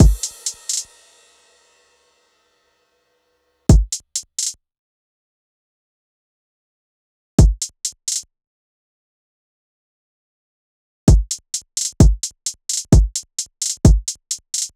SOUTHSIDE_beat_loop_banger_full_01_130.wav